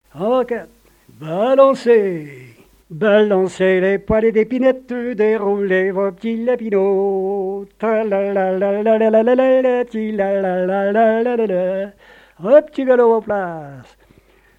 Quadrille
danse : quadrille : avant-quatre
répertoire de chansons et airs à l'accordéon
Pièce musicale inédite